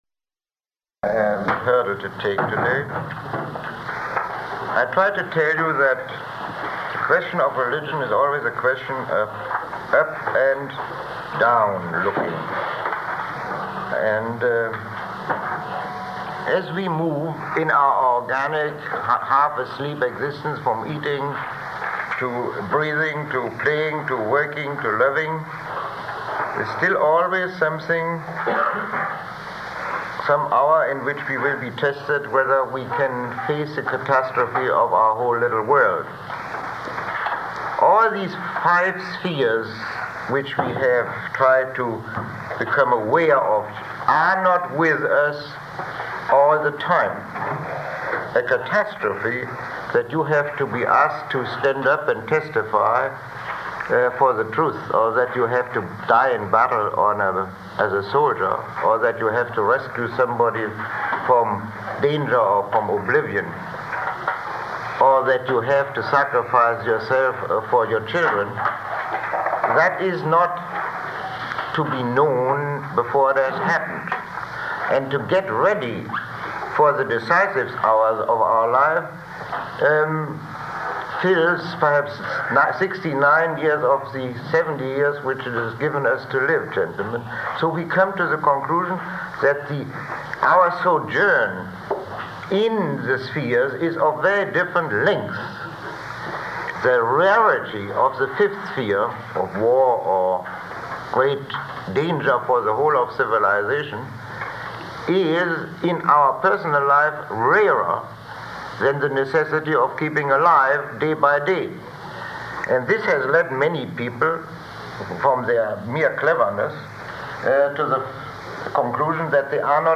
Lecture 22